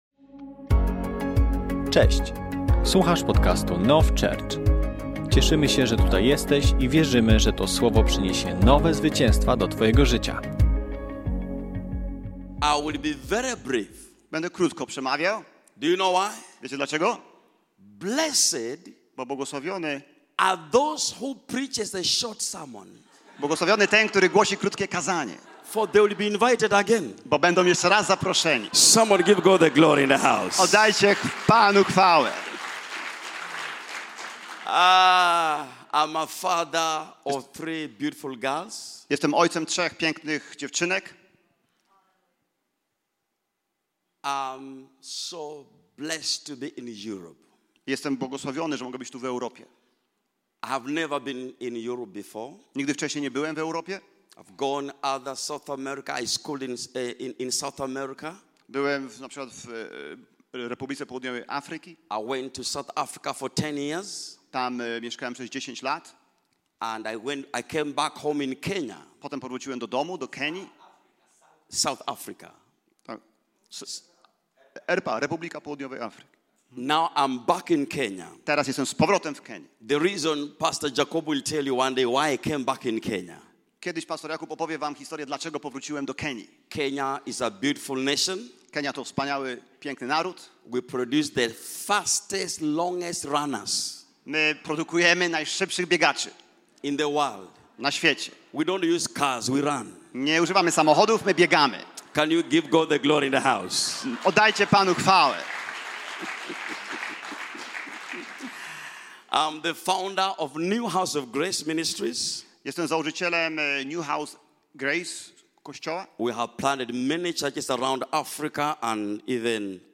Kazanie zostało nagrane podczas niedzielnego nabożeństwa NOF Church 09.03.2025 r. Download episode Share Share Copy URL Subscribe on Podcast Addict